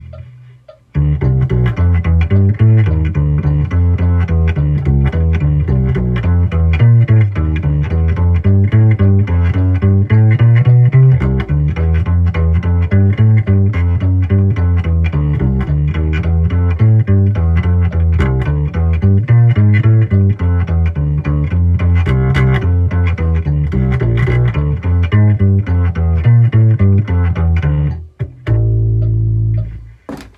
BP-100とマグネットPUをブレンドした音
まず両方のピックアップのボリュームを最大にして録音したもの。
ローはマグネット、ハイはBP-100の良いところが出てる気がする。
BP-100が弦が指板に当たる音をよく拾ってくれるおかげで泥臭い感じは出てきて悪くない。